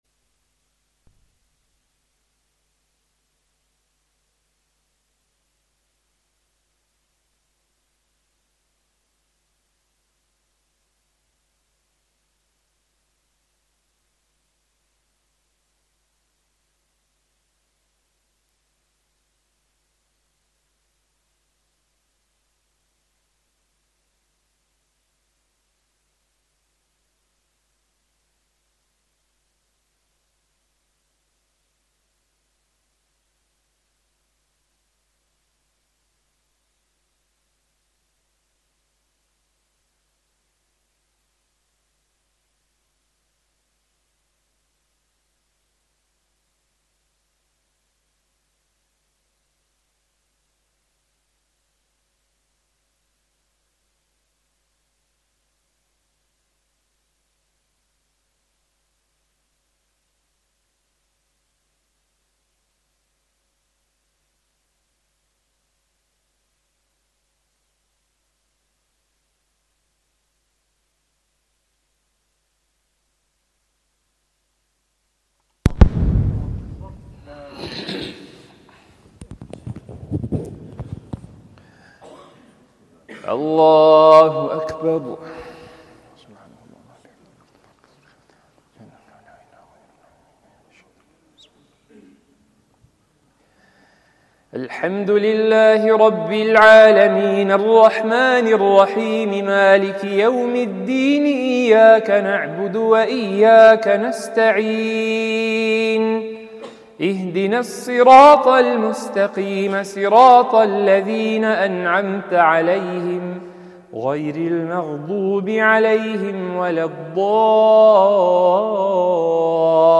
1st Annual Khanqahi Ijtema - Dars & Zikr Majlis